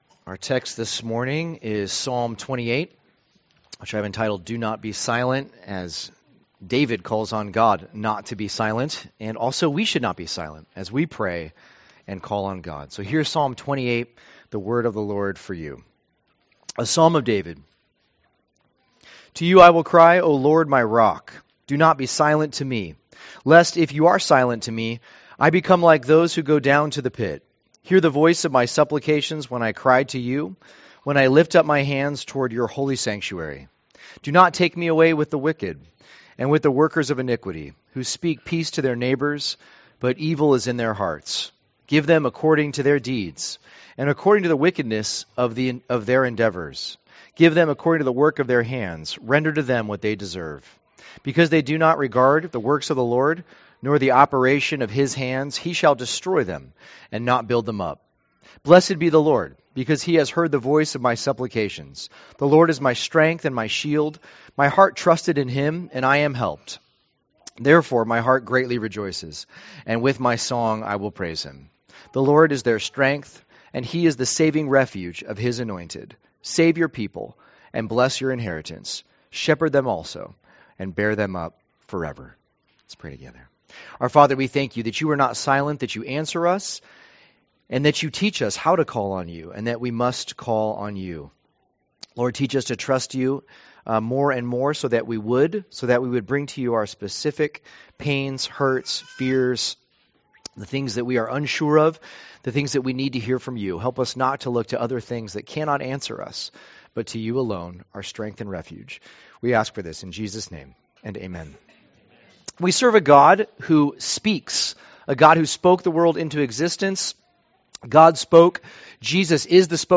2023 Do Not Be Silent Preacher